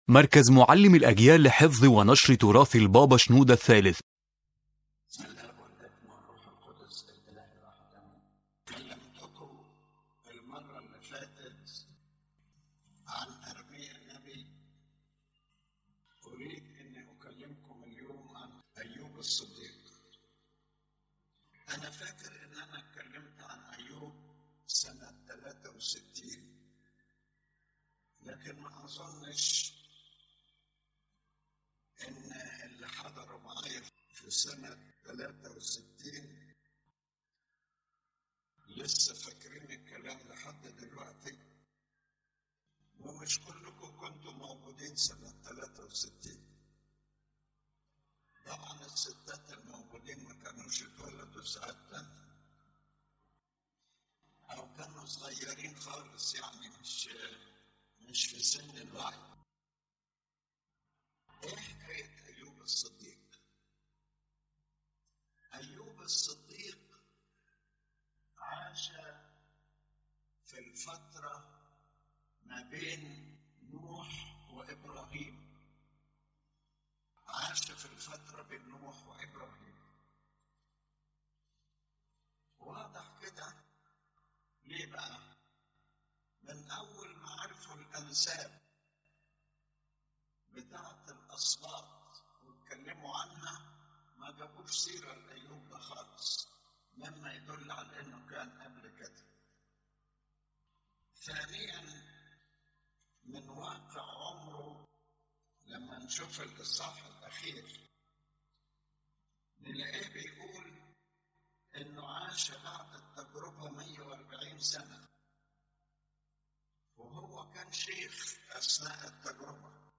The lecture speaks about the character of Righteous Job as a unique model of patience and steadfast faith amid severe trials.